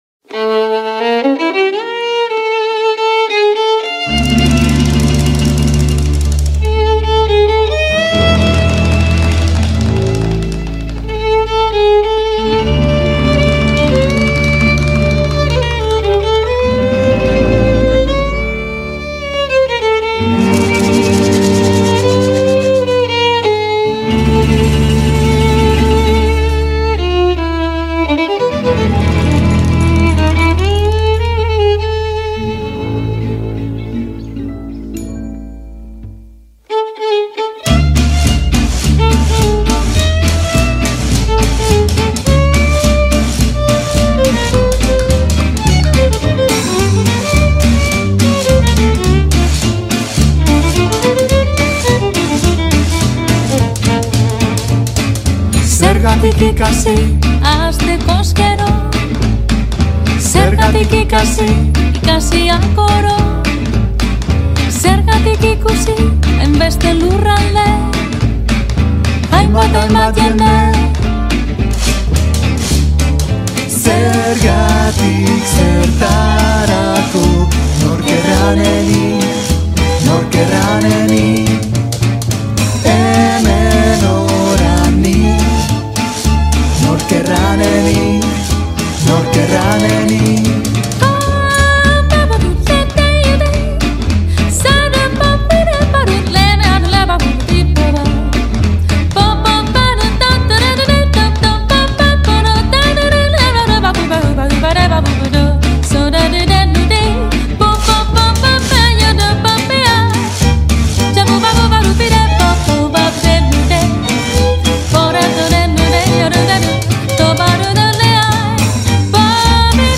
Irailaren 12an Getxo Folken ariko dira, eta horren harira gonbidatu ditugu gaur gurekin solastatzera.